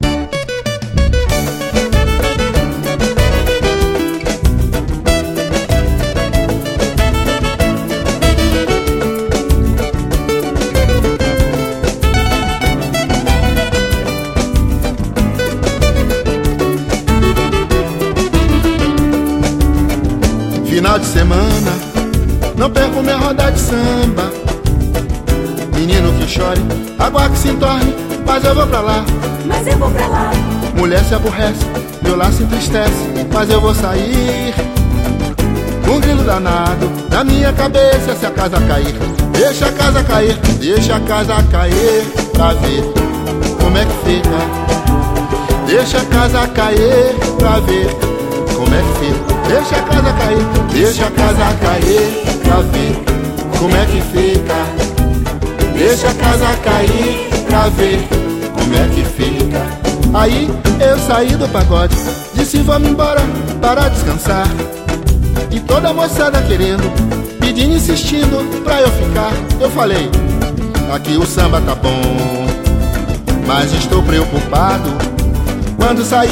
EstiloSamba
Cidade/EstadoSalvador / BA